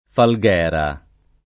[ fal g$ ra ]